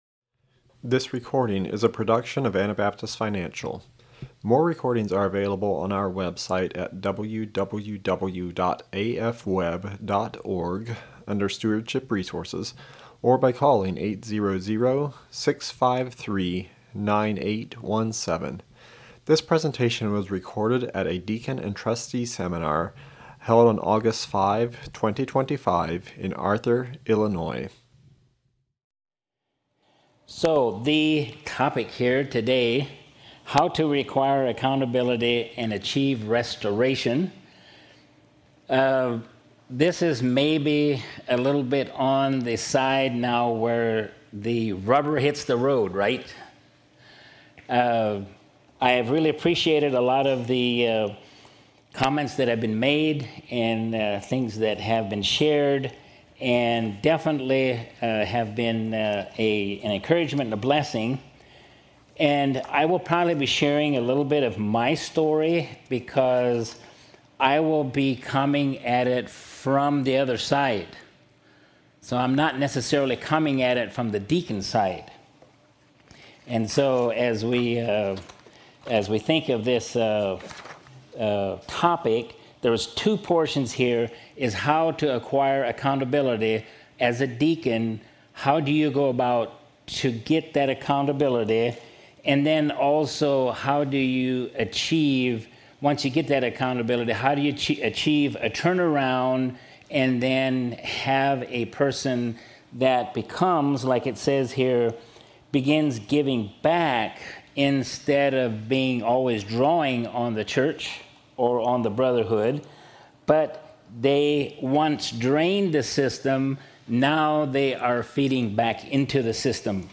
This is a recording from the Seminar for Deacons, Financial Advisors, and Trustees held in Arthur, IL in 2025.